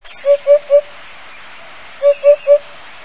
La huppe fasci�e, Upupa epops.
chant_huppe.mp3